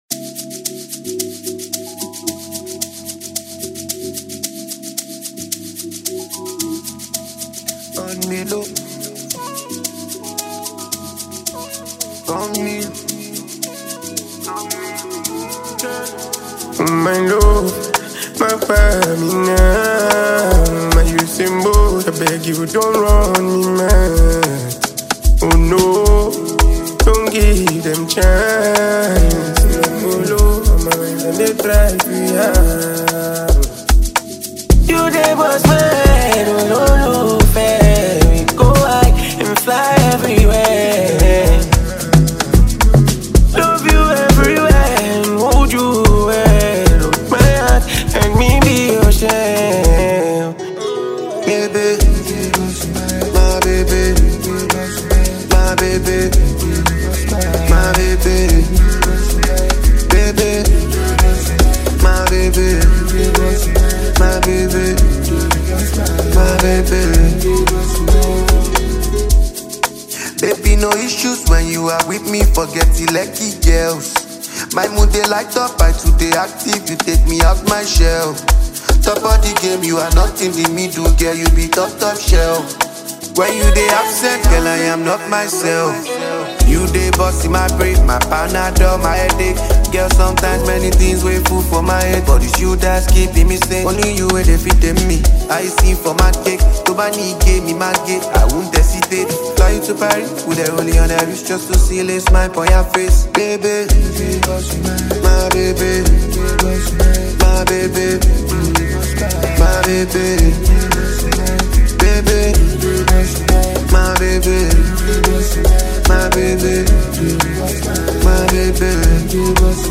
remix version
Ghanaian singer